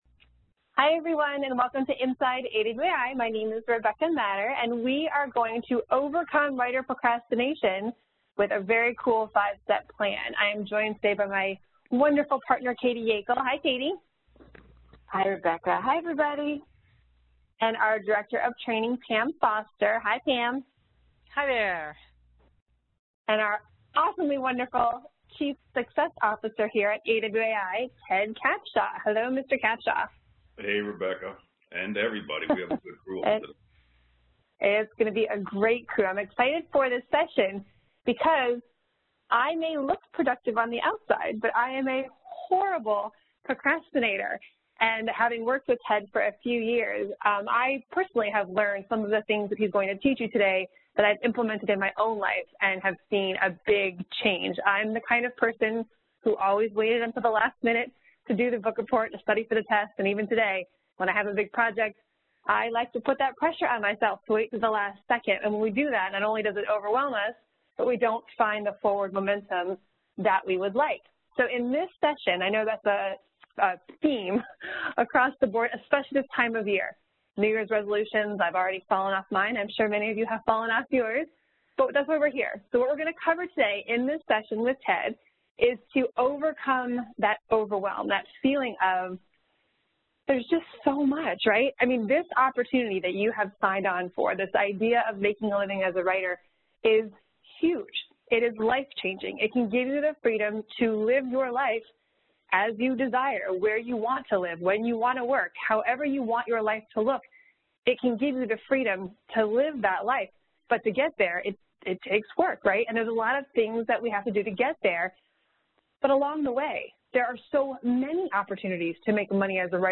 Inside AWAI Webinar and Q&A: Overcome Writer Procrastination with this 5-Step Plan